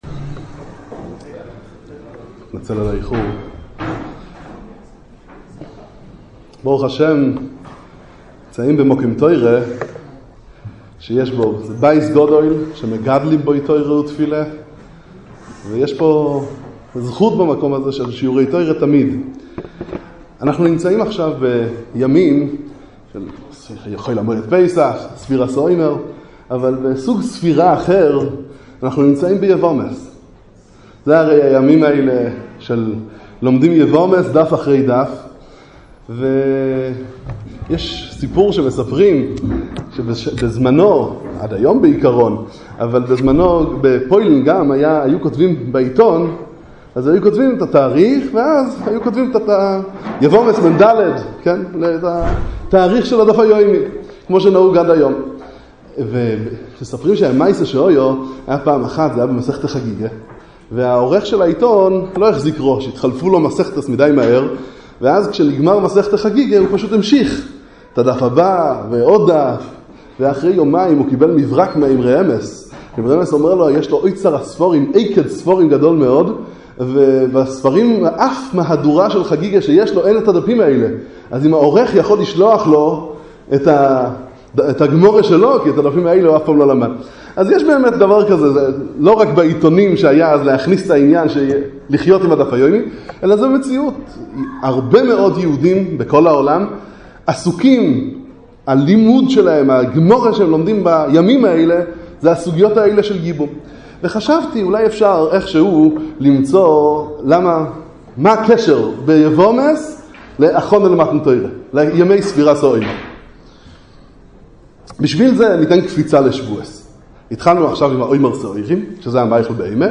לימוד יבמות כהכנה למתן תורה - בועז ורות - שיחת חג בביהמ"ד אונגוואר בירושלים